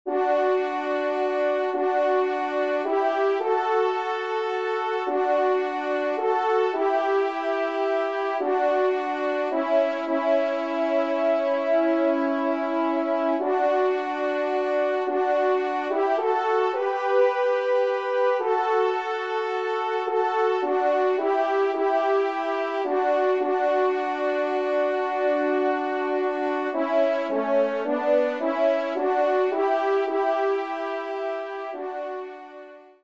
2e Trompe ou Cor